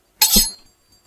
Other Sound Effects / RPG Sounds Pack 4
sword.5.ogg